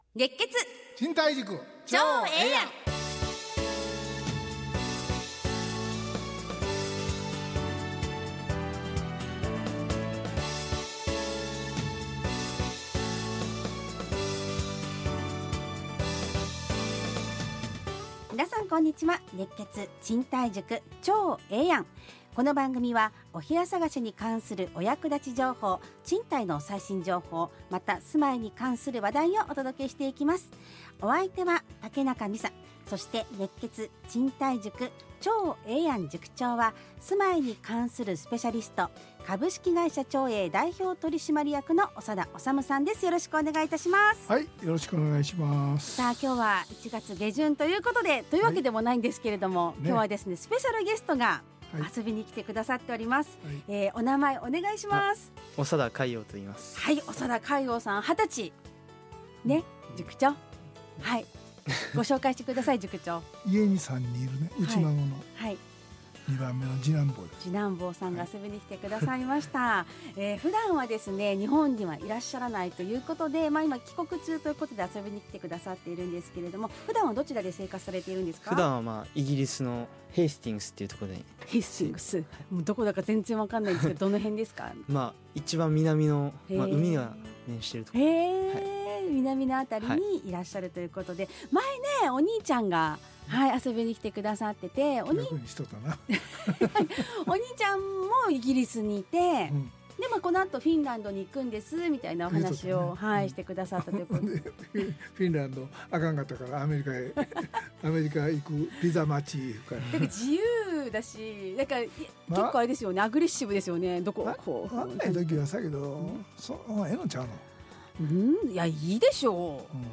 ラジオ放送 2026-01-23 熱血！